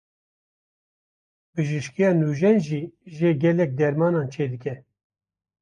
Pronounced as (IPA)
/nuːˈʒɛn/